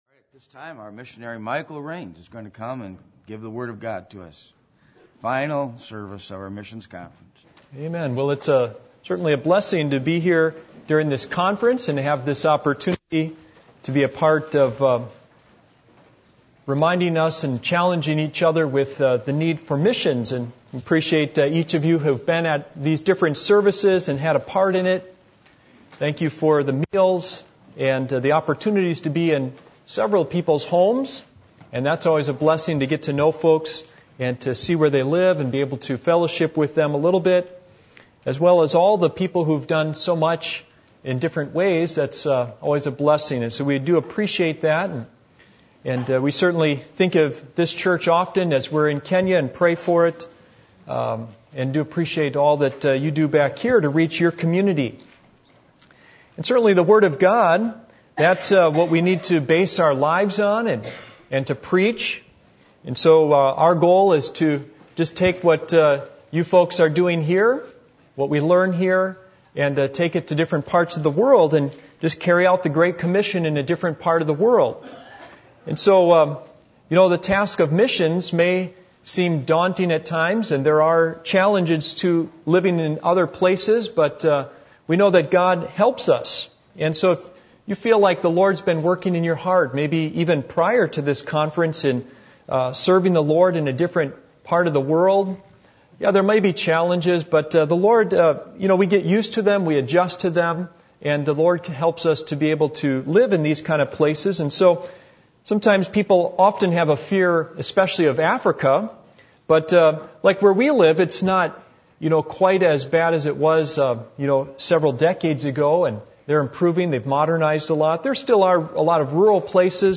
Missions Conference 2015 Passage: Luke 15:11-24 Service Type: Sunday Evening %todo_render% « How Do We Show Real Compassion?